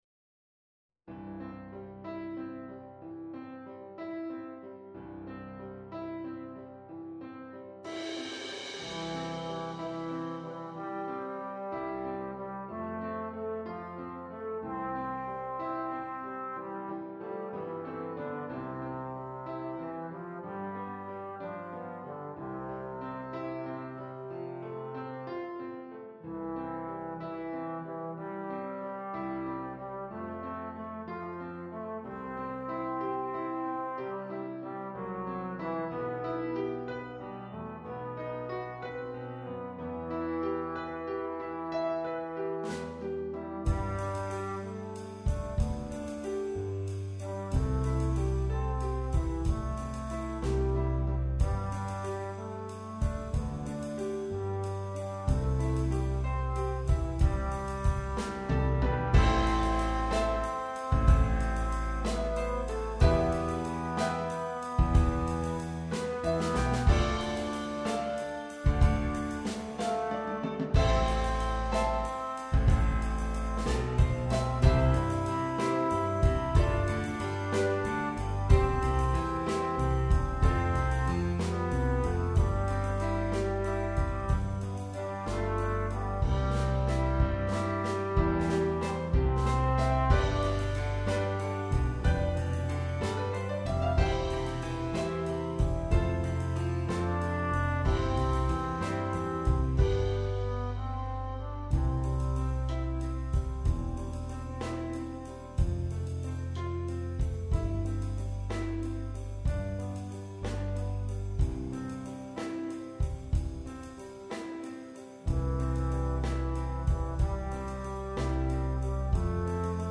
traditional Christmas Carol
Hymns